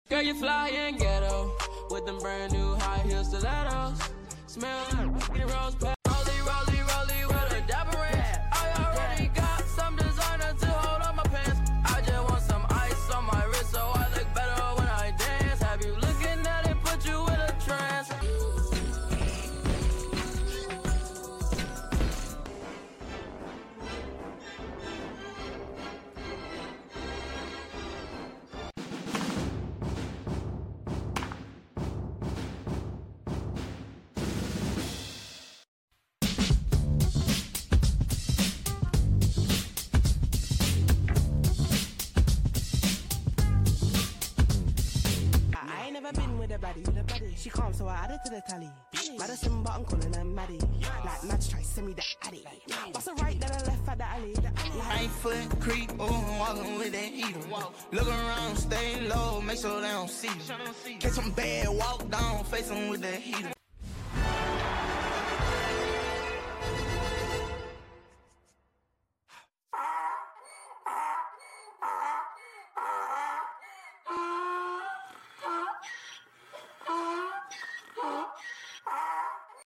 Top 10 Most Used Fortnite Sound Effects Free Download